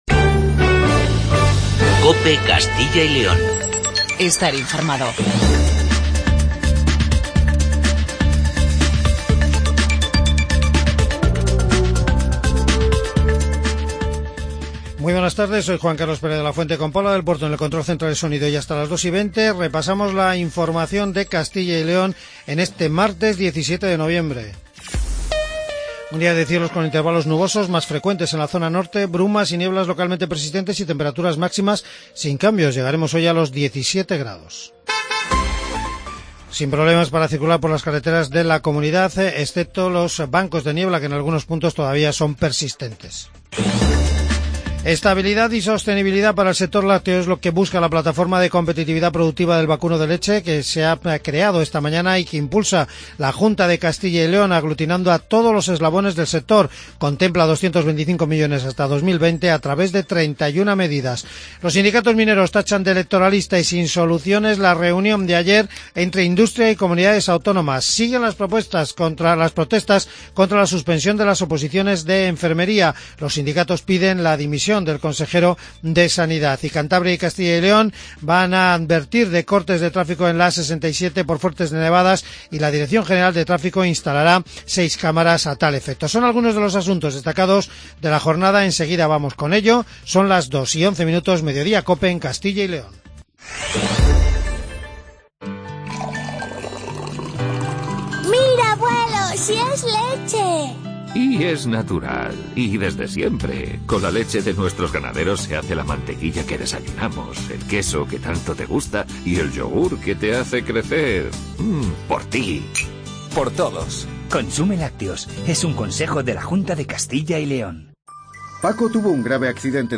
AUDIO: Informativo regional